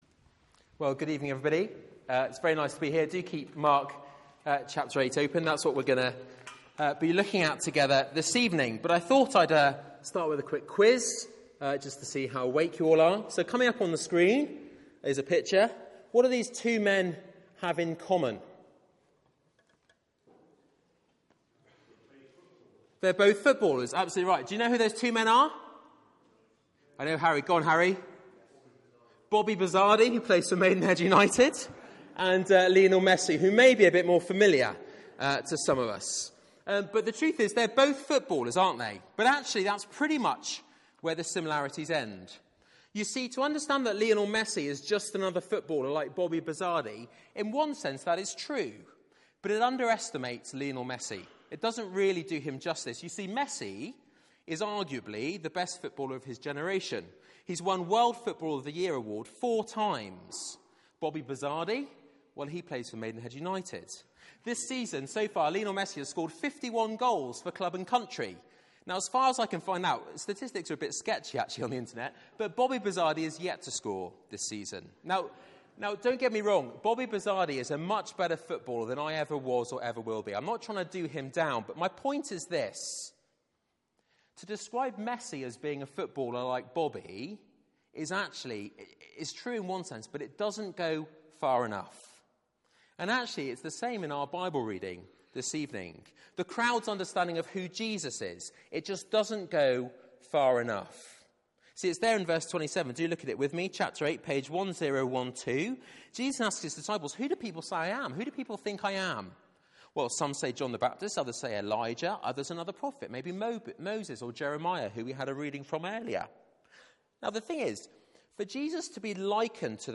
Mark 8:22-38 Service Type: Weekly Service at 4pm Bible Text